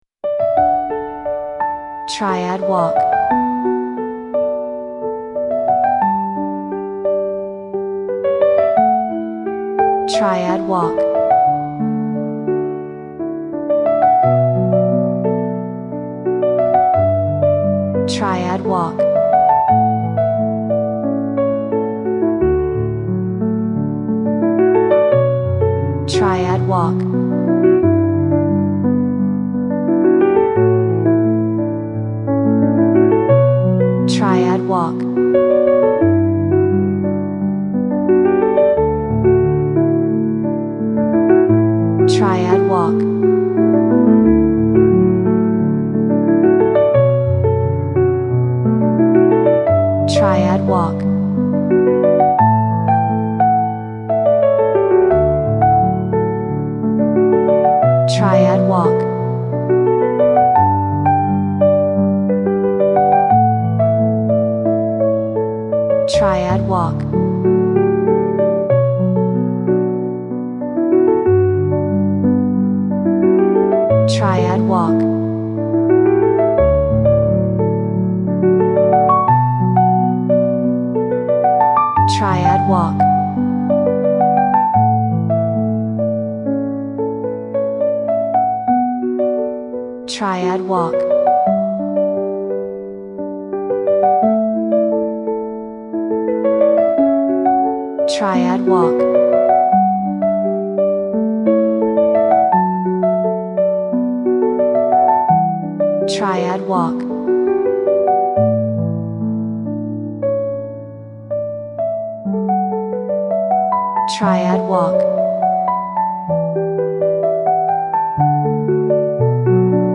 Piano
New Age